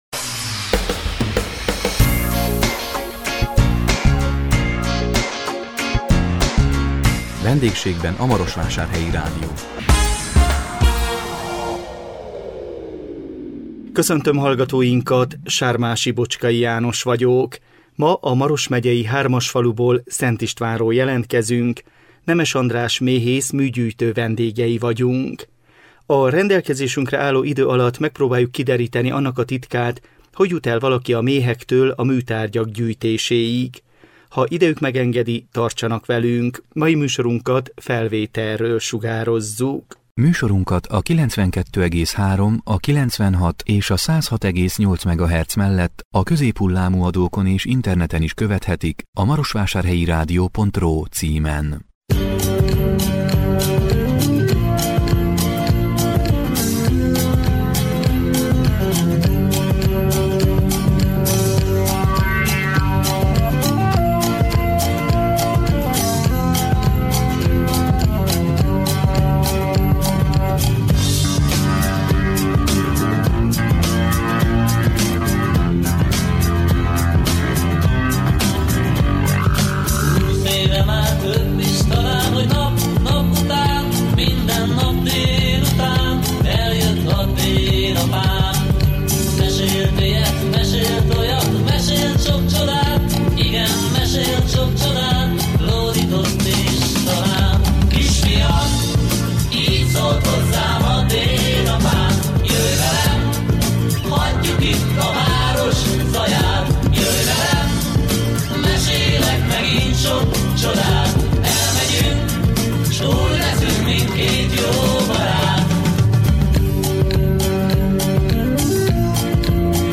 A 2022 augusztus 4-én jelentkező VENDÉGSÉGBEN A MAROSVÁSÁRHELYI RÁDIÓ című műsorunkkal a Maros megyei Hármasfaluból, Szent Istvánról jelentkeztünk